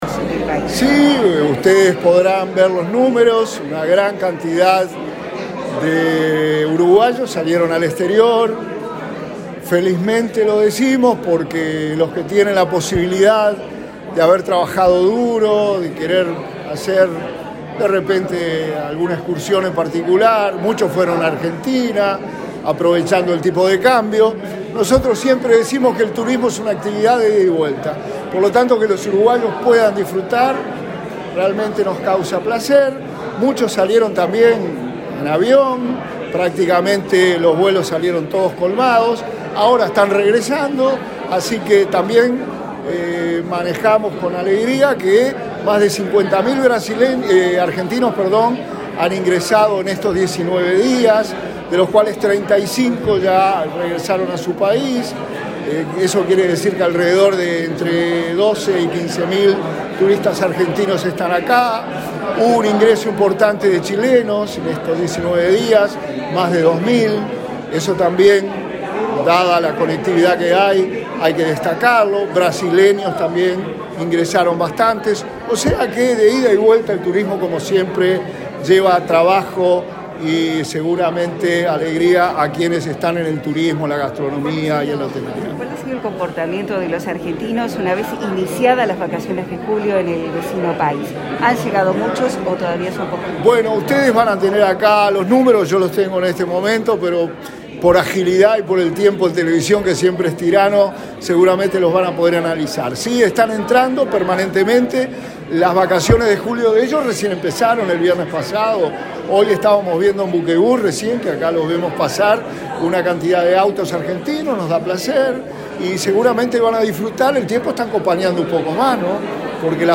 Declaraciones del subsecretario de Turismo, Remo Monzeglio
El subsecretario de Turismo, Remo Monzeglio, dialogó con la prensa sobre el ingreso y egreso de turistas en vacaciones de julio y las acciones futuras